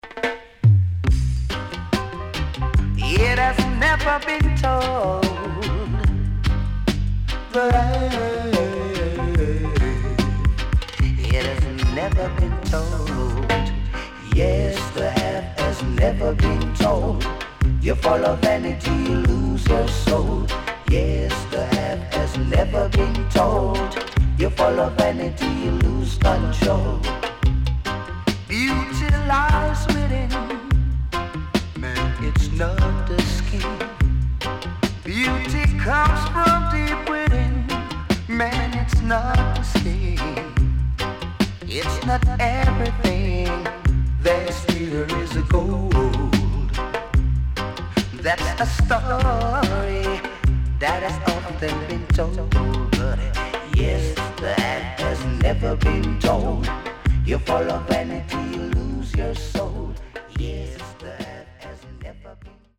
Early 80's Killer One Drop Tune 良曲多数収録